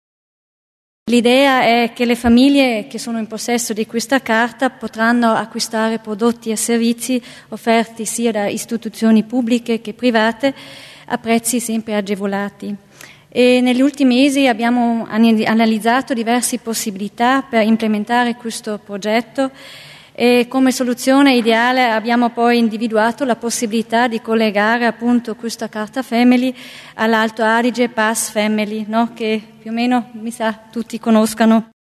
L'Assessore Deeg spiega le novità in tema di Family Pass